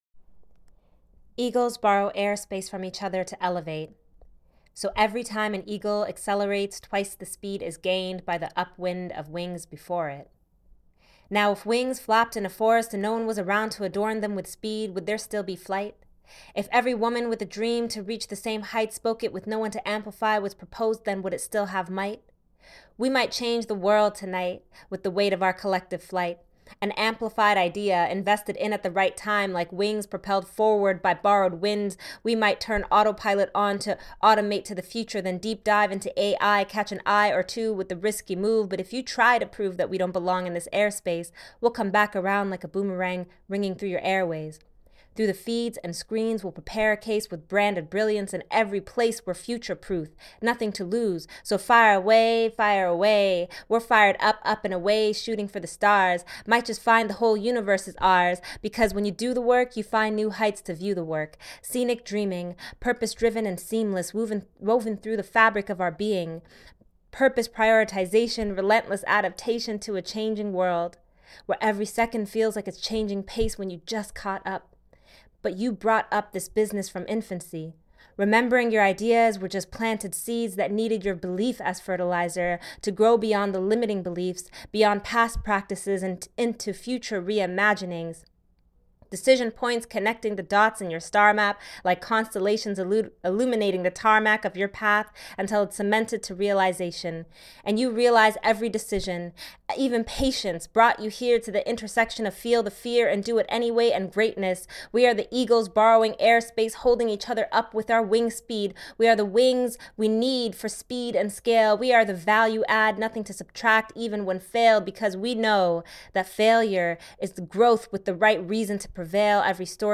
An image of a muslim woman wearing a hijab speaking into a microphone.